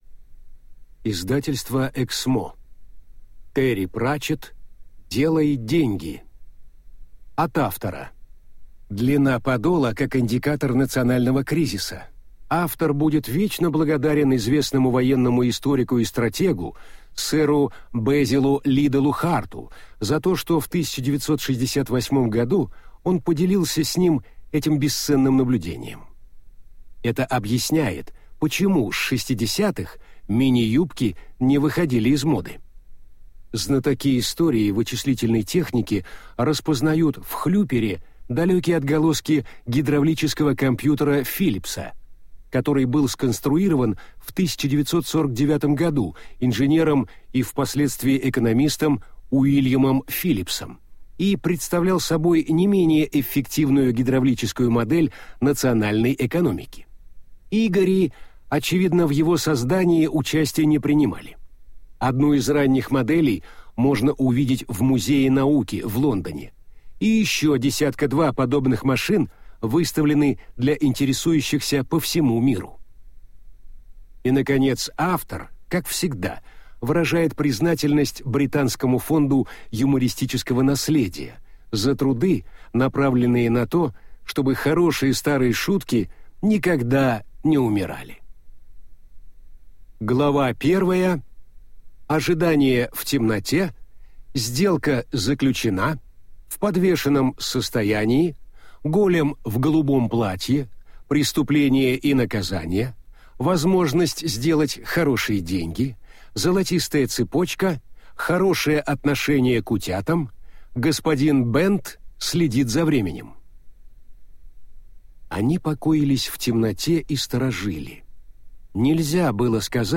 Аудиокнига Делай деньги!